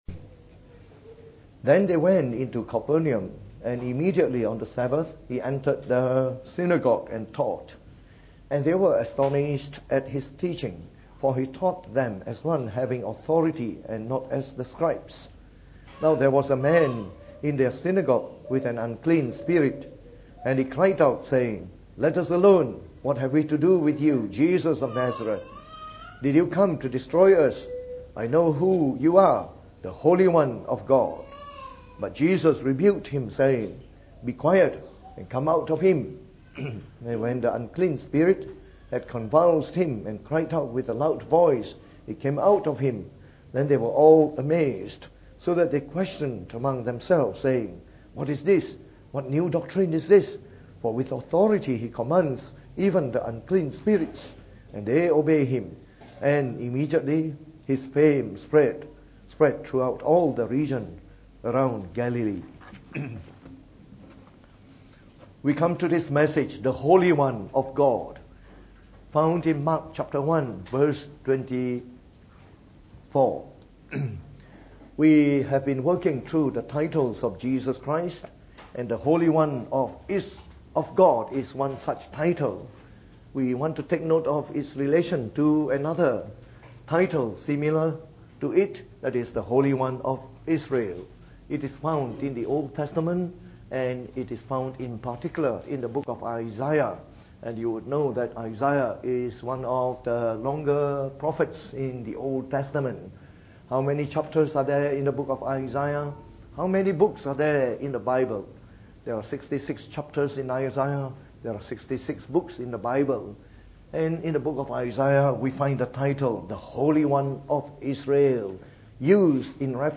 Preached on the 11th of March 2018. From our series on the Titles of Jesus Christ delivered in the Evening Service.